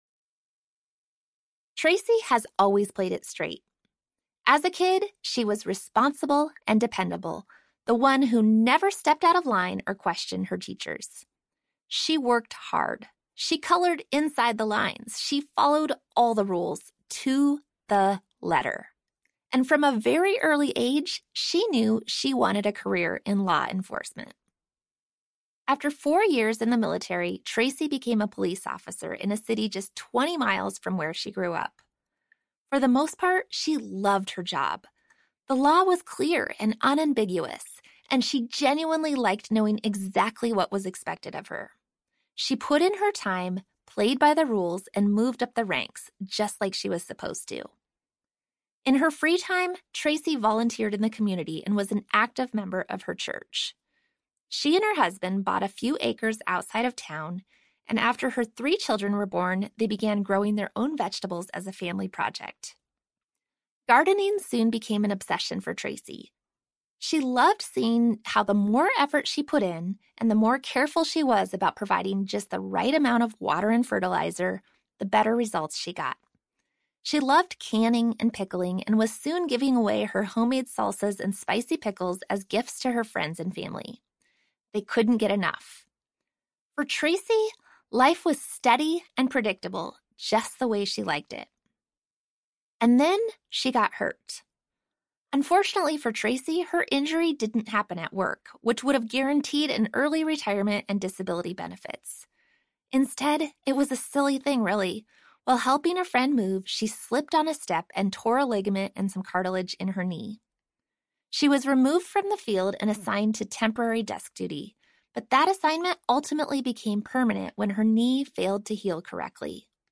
Do It Scared Audiobook